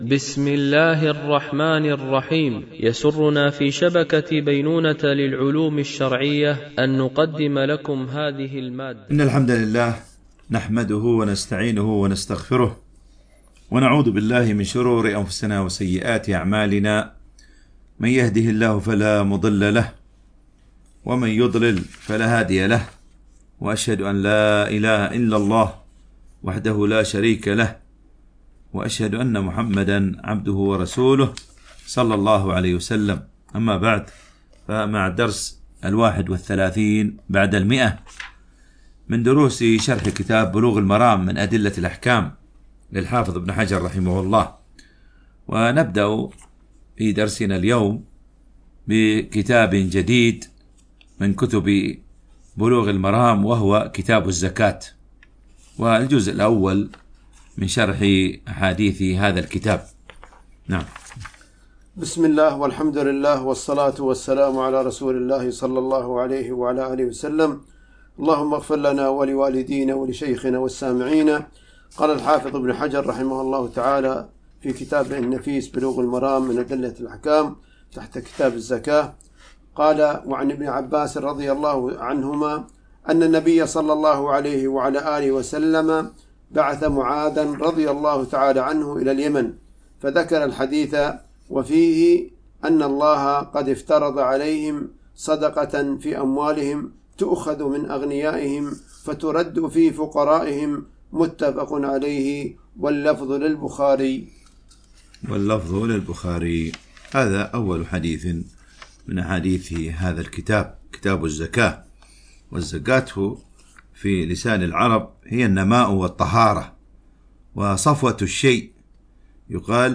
شرح بلوغ المرام من أدلة الأحكام - الدرس 131 ( كتاب الزكاة - الجزء الأول - الحديث 599 - 600 )